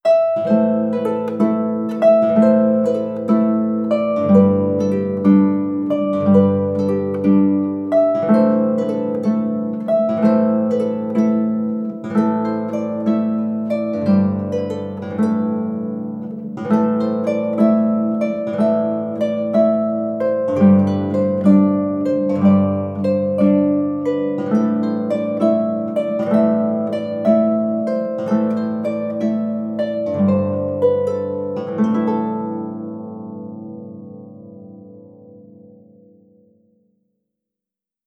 • ana ebony harp traditional sequence.wav
ana_ebony_harp_traditional_sequence_ucc.wav